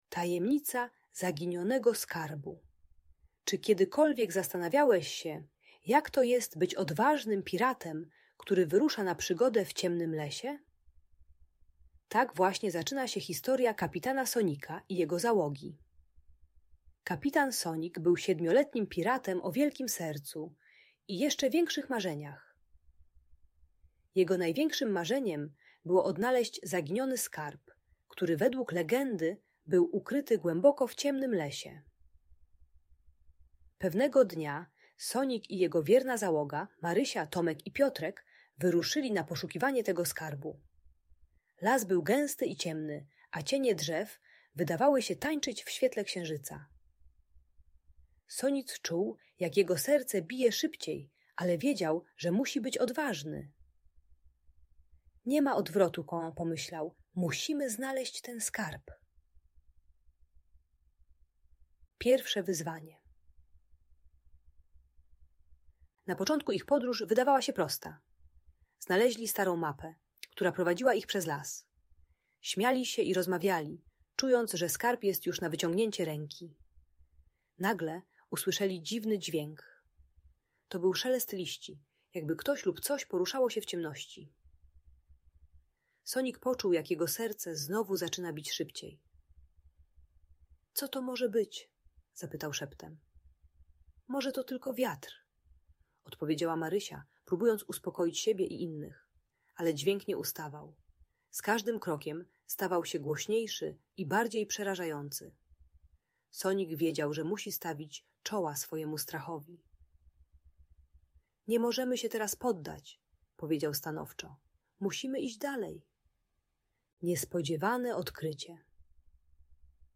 Tajemnica Zaginionego Skarbu - Audiobajka